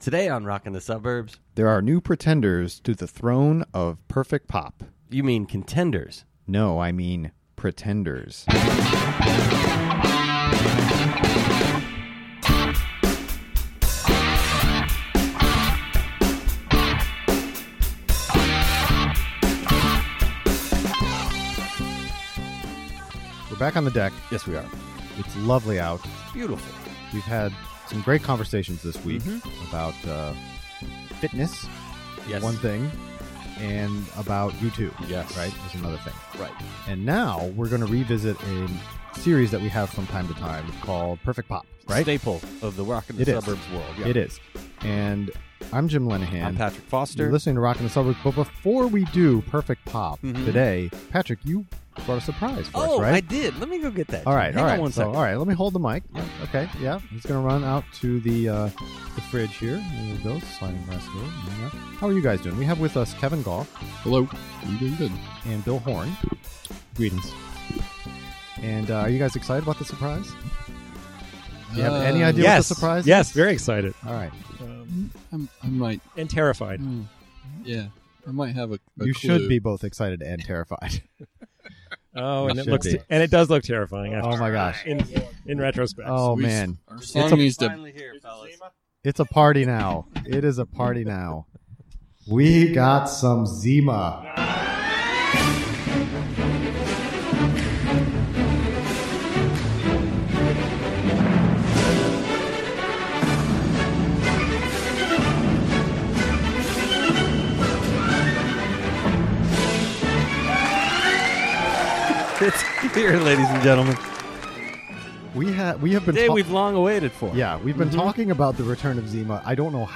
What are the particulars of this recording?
It's a backyard Zima party! And yet we talk about perfect pop songs from the pre-brewed citrus beverage era.